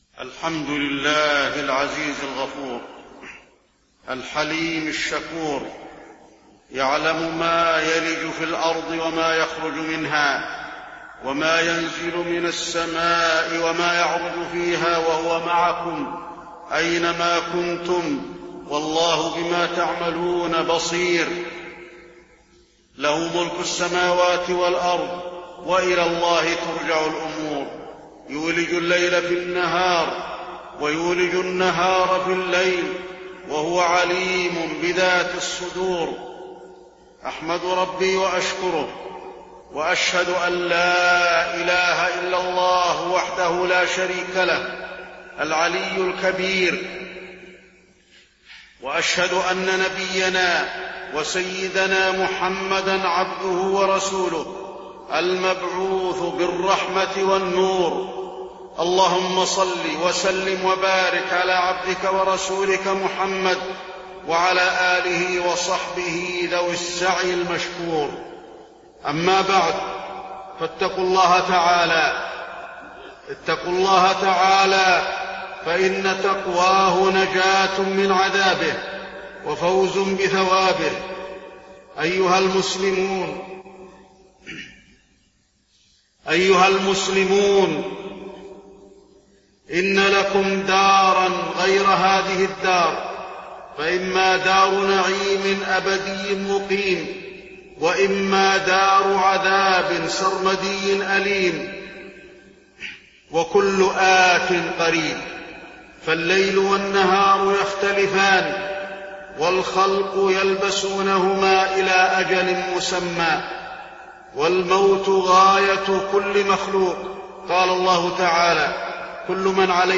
تاريخ النشر ٦ جمادى الأولى ١٤٢٧ هـ المكان: المسجد النبوي الشيخ: فضيلة الشيخ د. علي بن عبدالرحمن الحذيفي فضيلة الشيخ د. علي بن عبدالرحمن الحذيفي اغتنام الأوقات